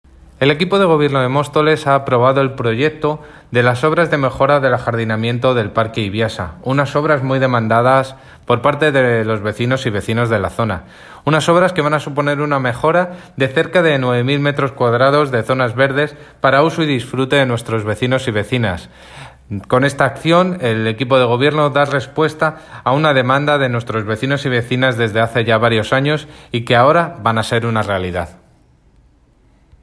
Declaraciones de David Muñoz Portavoz de Mejora y Mantenimiento de los Espacios Públicos - Obras del Parque Iviasa